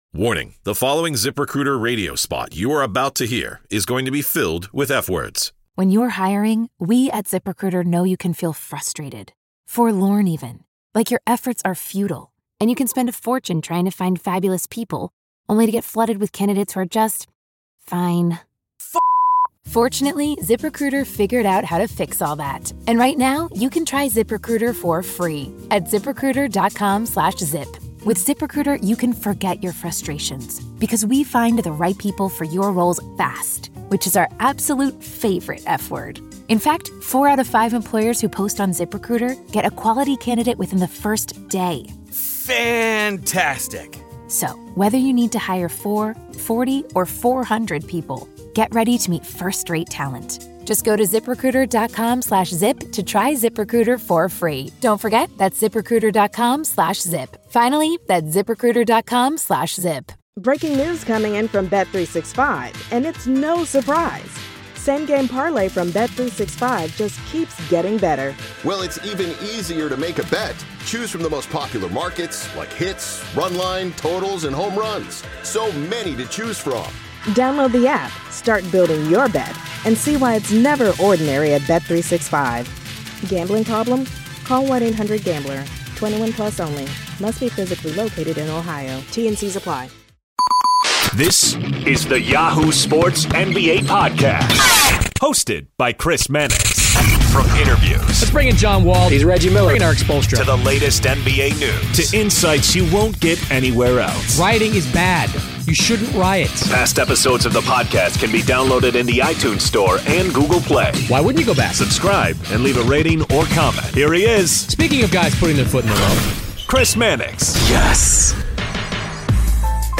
Caron Butler and Donovan Mitchell The Crossover NBA Show SI NBA Basketball, Sports 4.6 • 641 Ratings 🗓 27 March 2018 ⏱ 56 minutes 🔗 Recording | iTunes | RSS 🧾 Download transcript Summary Joining Chris Mannix of Yahoo Sports this week is Caron Butler, two-time NBA All-Star and the co-host of Chris and Caron on Fox Sports Radio.